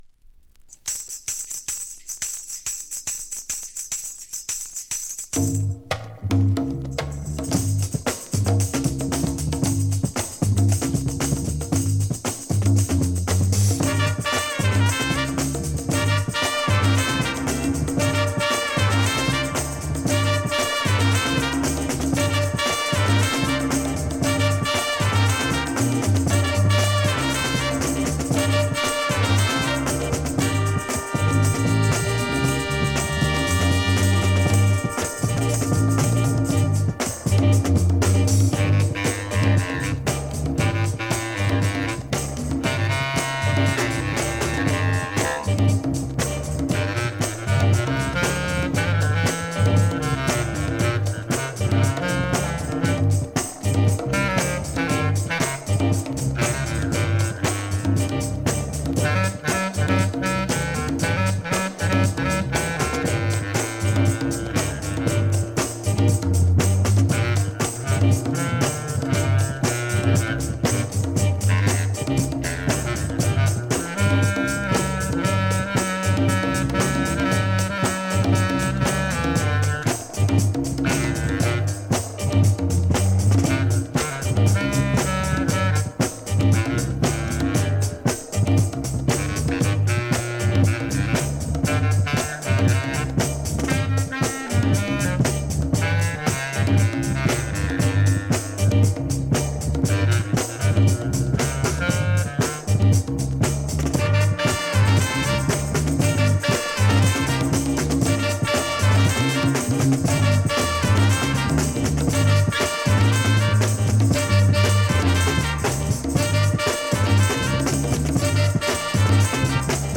SOUL、FUNK、JAZZのオリジナルアナログ盤専門店
◆盤質Ａ面/EX+ ◆盤質Ｂ面/EX+